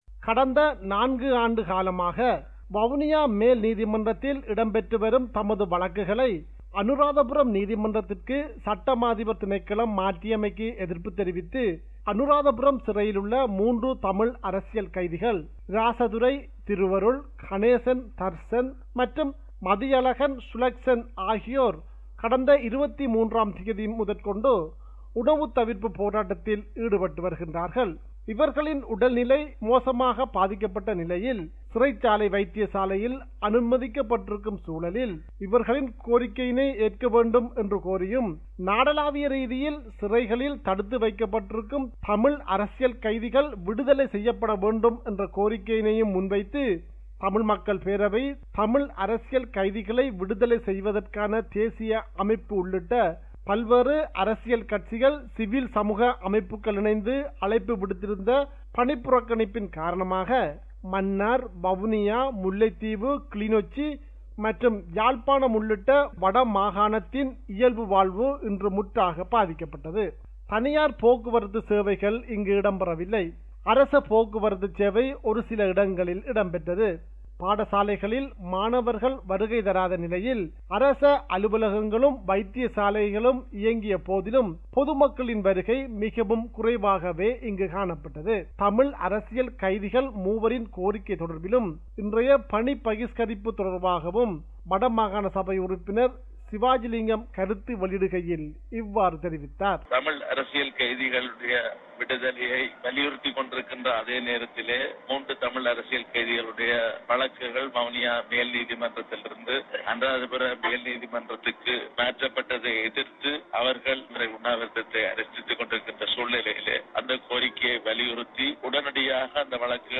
compiled a report focusing on major events/news in North & East / Sri Lanka.